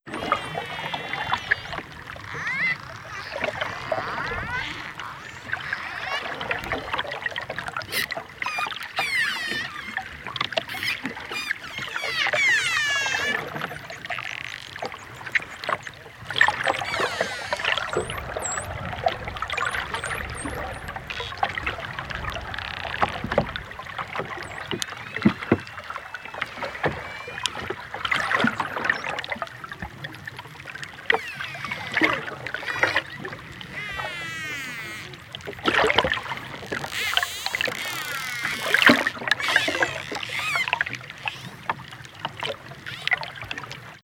• dolphins speaking.wav
dolphins_speaking_nRF.wav